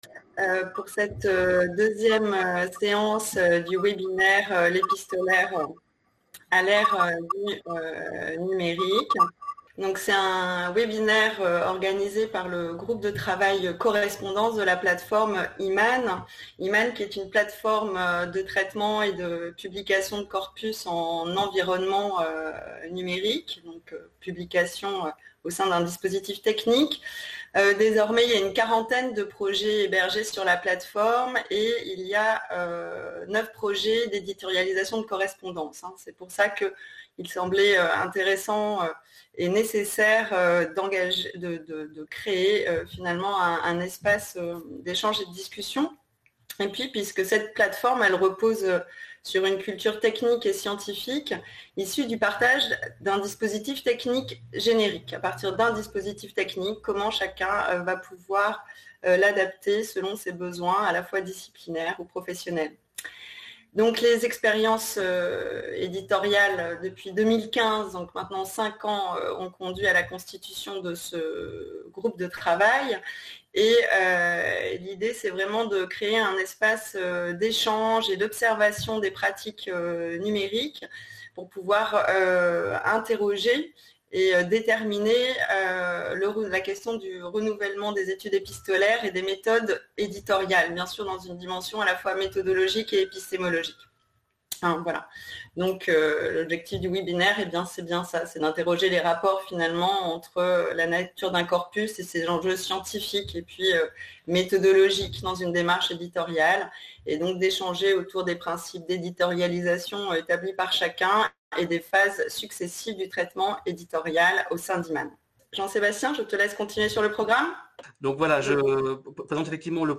Séminaire L'épistolaire à l'ère du numérique.